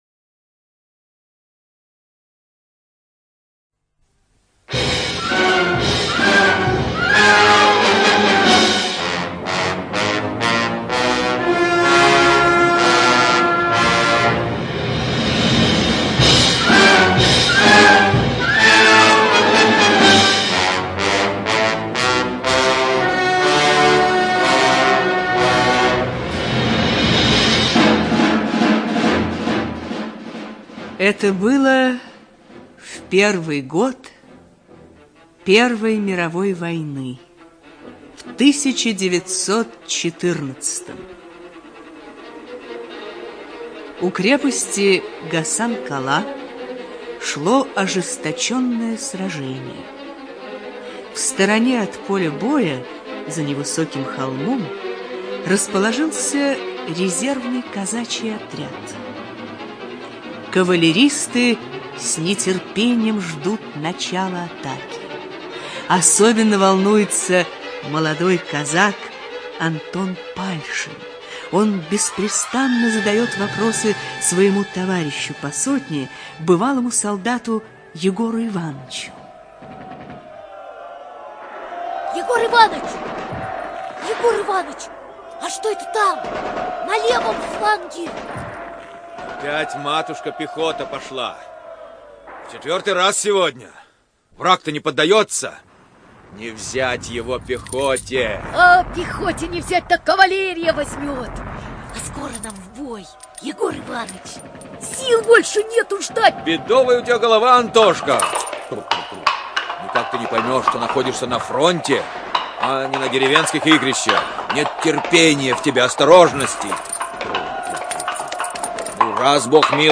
ЖанрРадиоспектакли